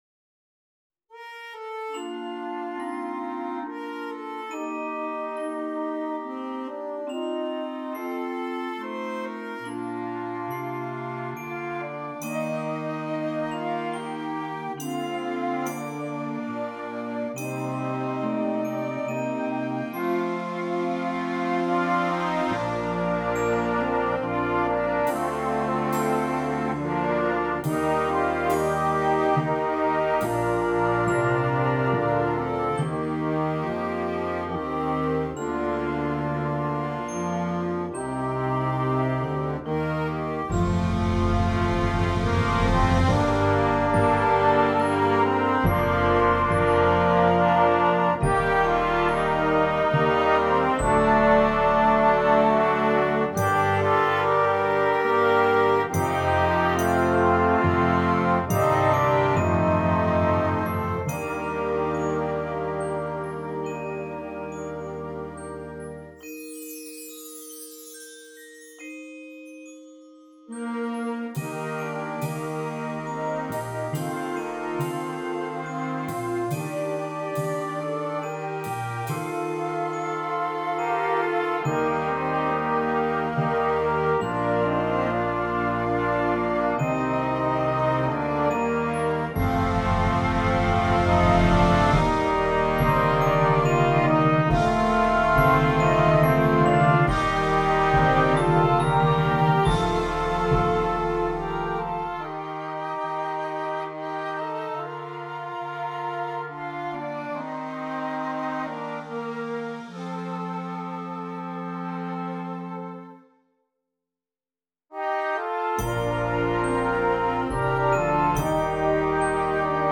Concert Band
gentle and playful, while being reflective at the same time